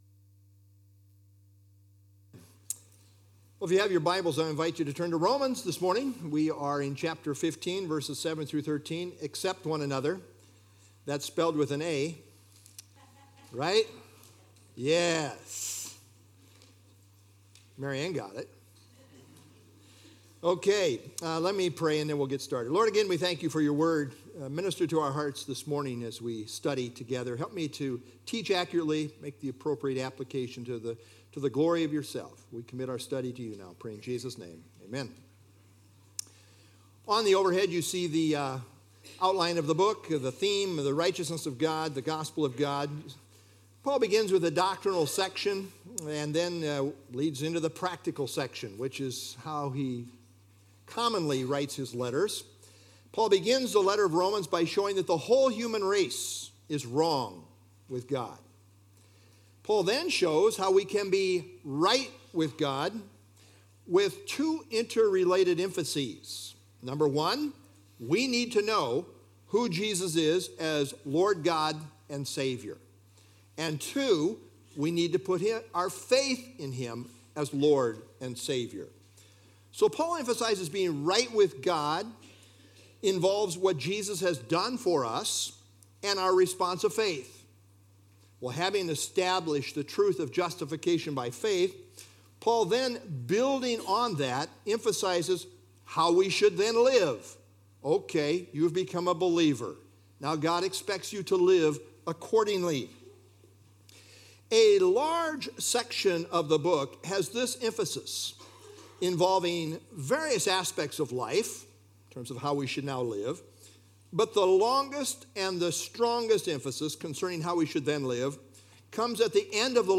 Download FilesRom 15 7-13 Sermon - Nov 24 2024Romans 15_7-13